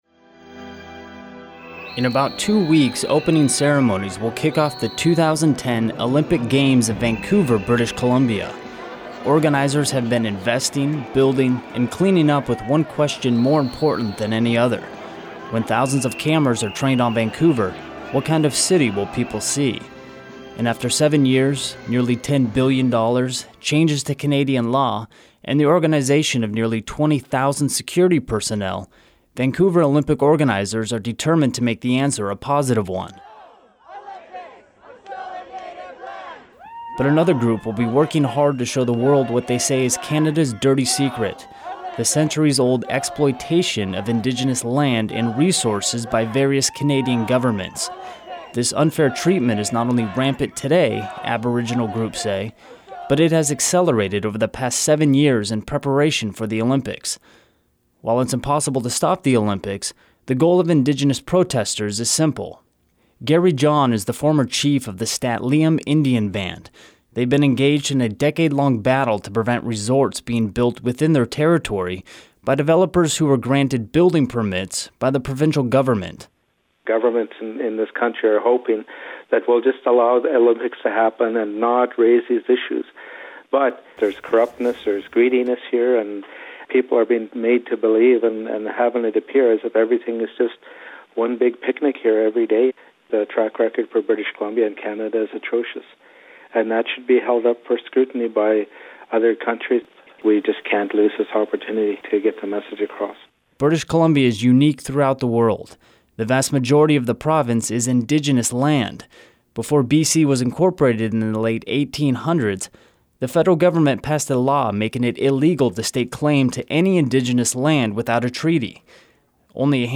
This story originally aired on Community Radio KBCS in Seattle.